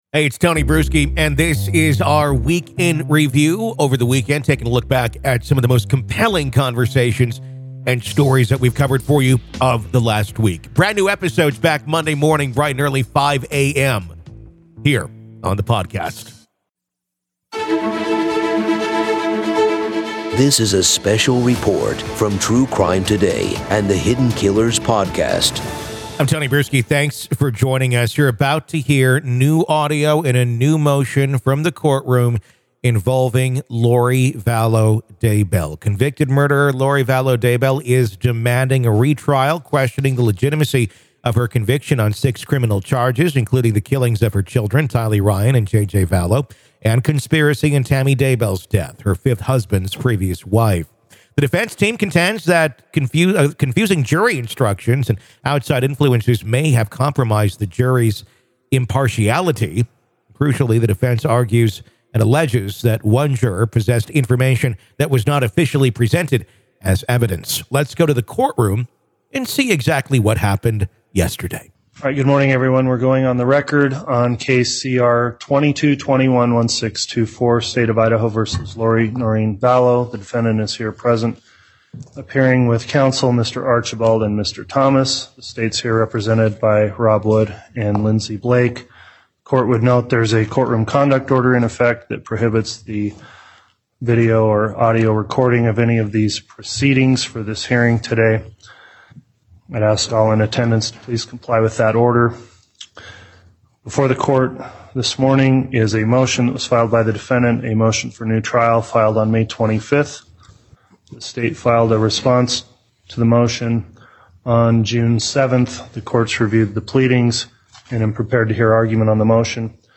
WEEK IN REVIEW-LIVE COURT AUDIO Lori Vallow Daybell Asking Judge For New Trial